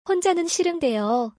ホンジャヌン シルンデヨ